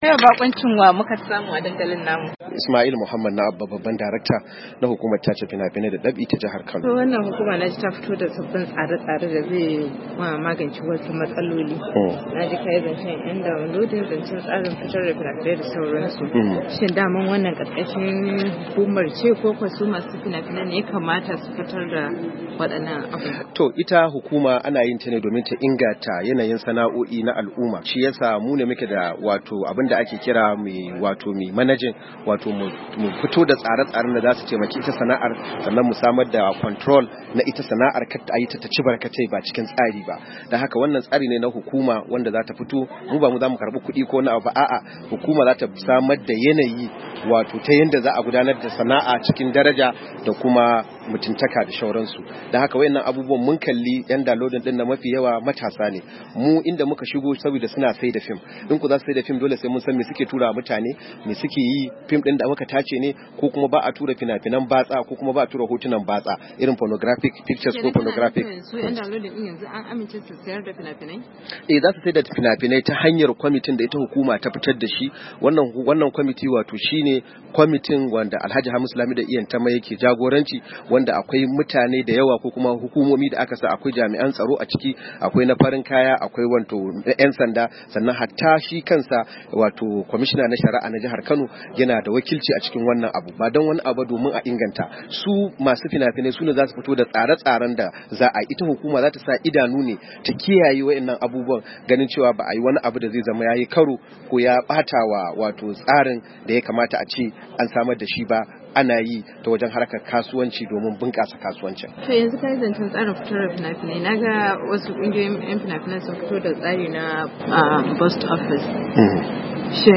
Ya bayyana haka ne a yayin da yake zantawa da Wakiliyar DandalinVOA inda ya bayyana cewa tun da farko da kungiyar MOPPAN ta dakatar da Rahma Sadau, hukumar tace fina-finan sai da ta binciki fina-finan jarumar tare da gindaya mata wasu sharudda da ta kiyaye na baiwa al’umma hakuri sa’annan ne hukumar ta bata damar gudanar da fina-finanta kamar kowa.